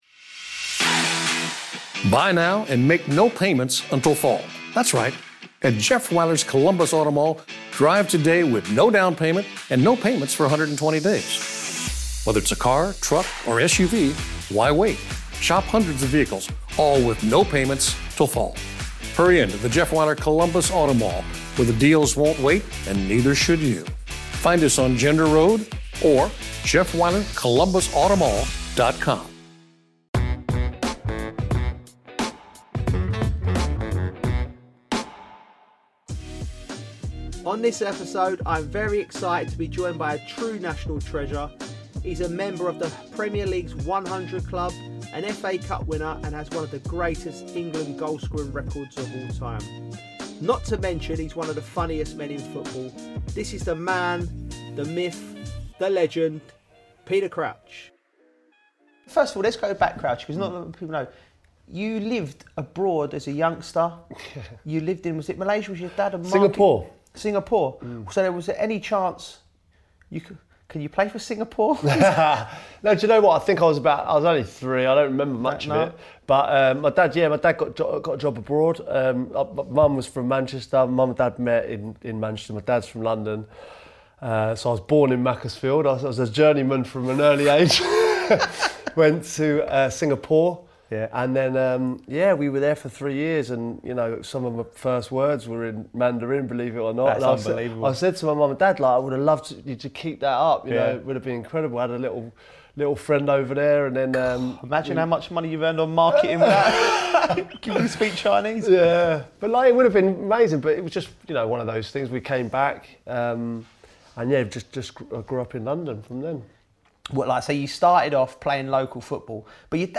The Joe Cole Cast starts with a bang as Joe and Peter open up about the lows of professional sport, share some hilarious training ground tales and Peter gives a rendition of one of football’s funniest chants.